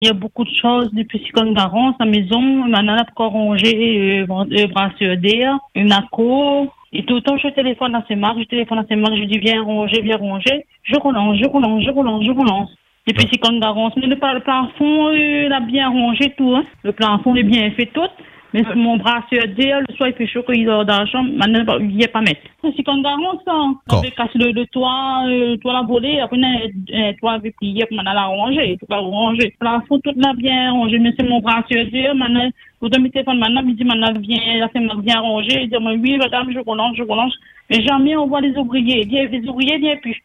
Elle nous raconte sa galère et son désarroi face à cette situation.